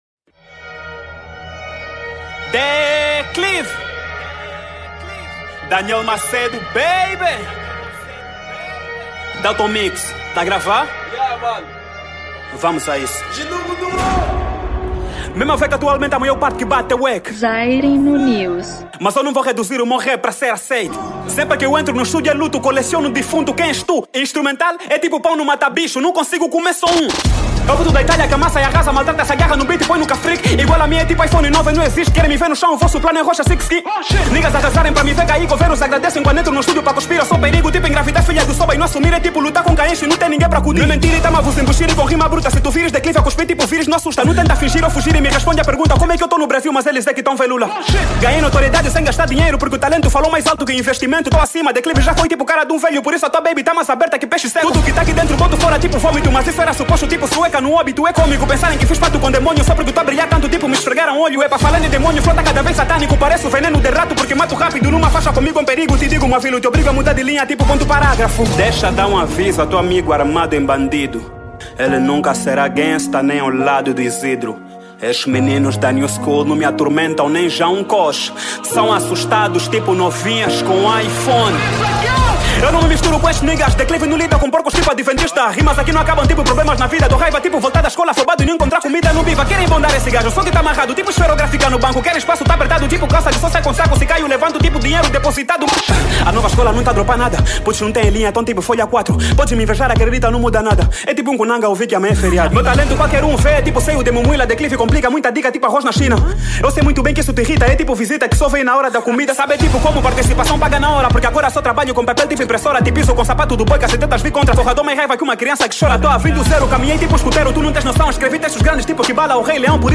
2021 Estilo : Rap Formato